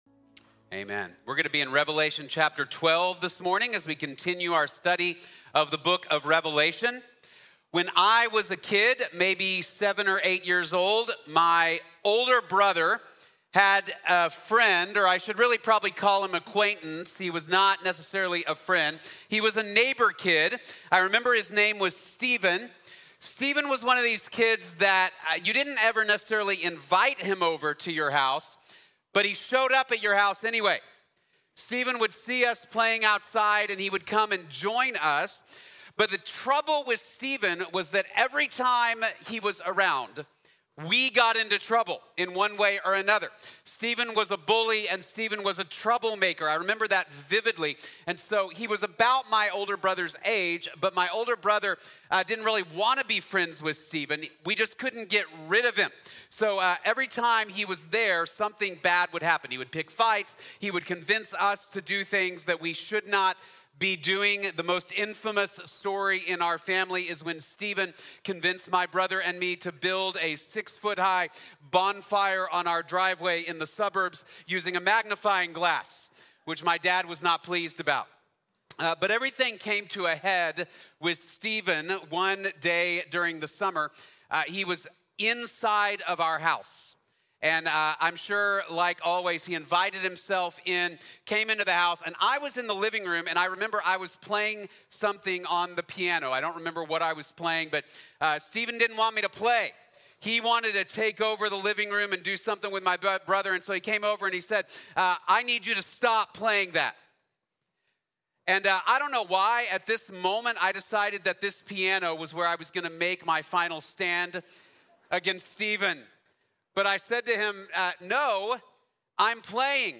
The Devil Will Not Win | Sermon | Grace Bible Church